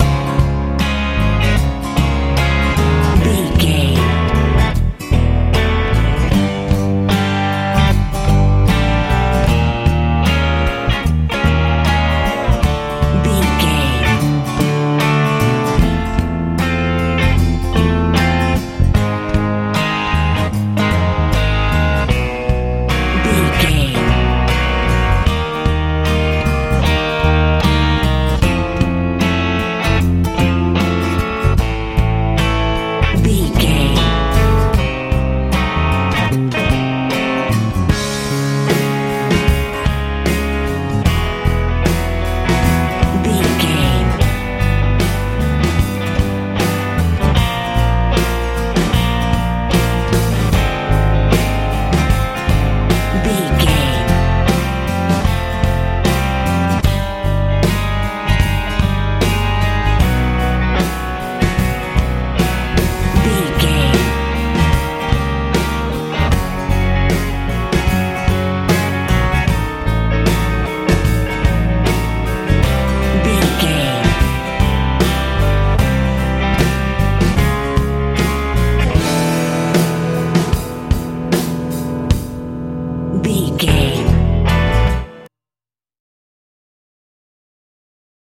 lite pop feel
Ionian/Major
C♯
light
bass guitar
drums
electric guitar
acoustic guitar
cheerful/happy